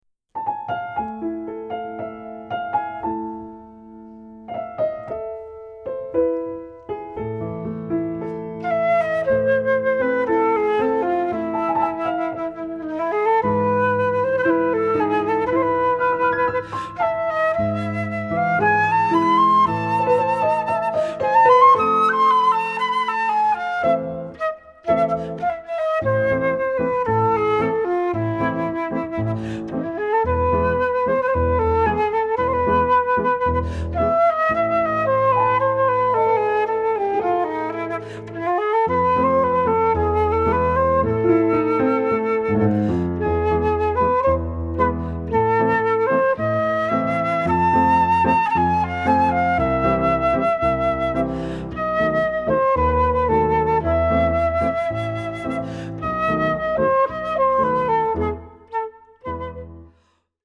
Pour flûte et piano